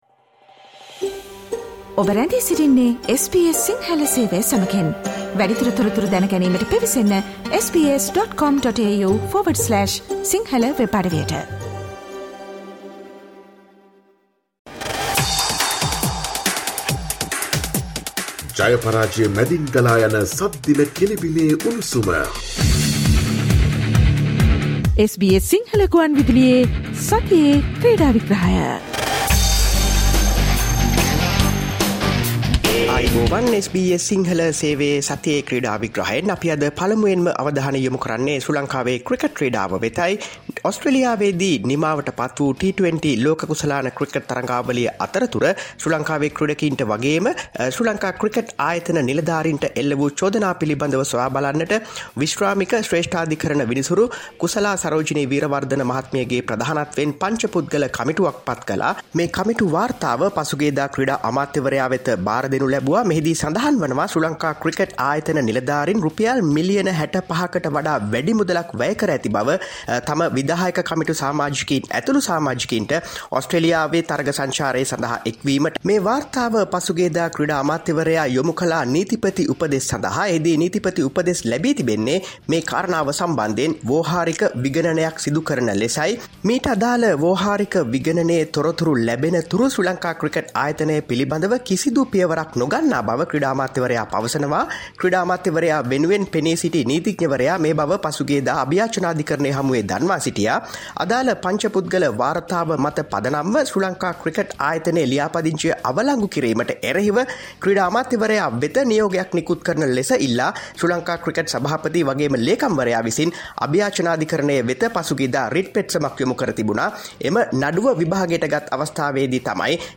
Listen to the SBS Sinhala Radio weekly sports highlights every Friday from 11 am onwards